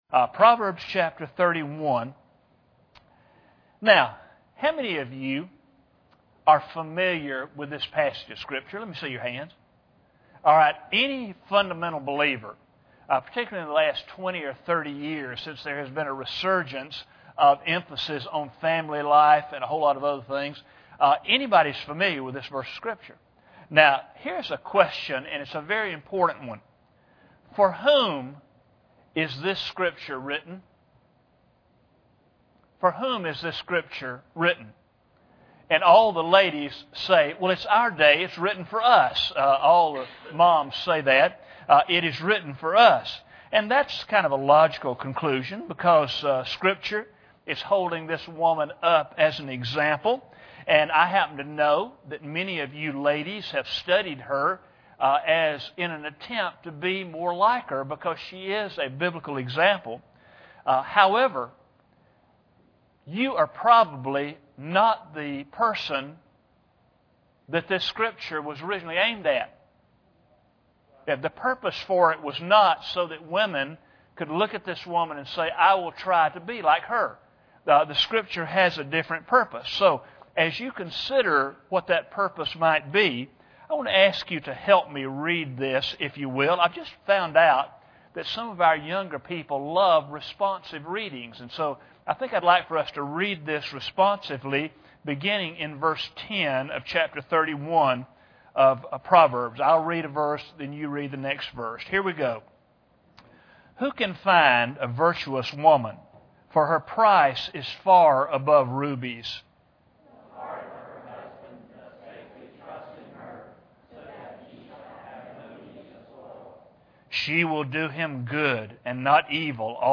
General Service Type: Sunday Morning Preacher